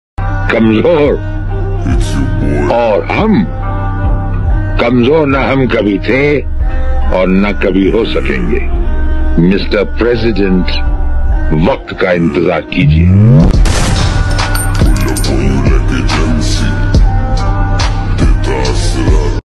2026 Model Honda 70 Kis Sound Effects Free Download